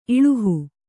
♪ iḷuhu